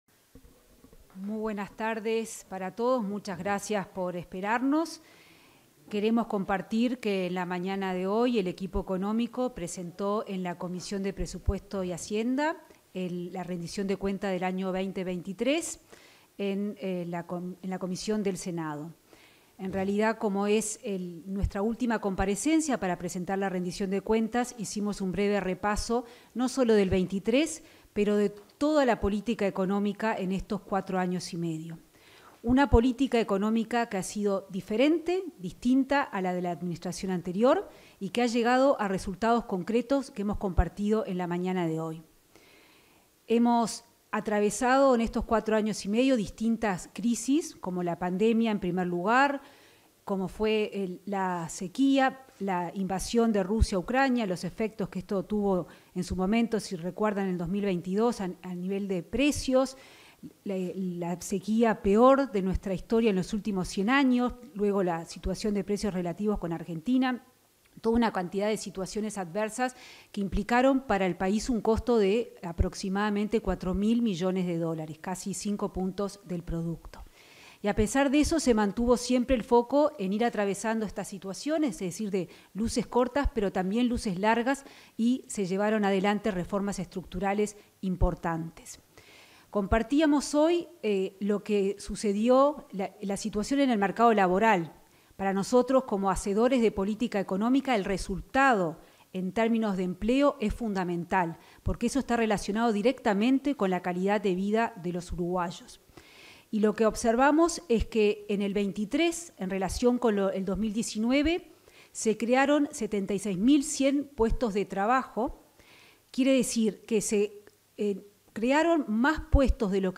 Conferencia de autoridades del equipo económico de gobierno
se expresaron la ministra de Economía y Finanzas, Azucena Arbeleche; el presidente del Banco Central del Uruguay (BCU), Washington Ribeiro, y el director de la Oficina de Planeamiento y Presupuesto (OPP), Fernando Blanco.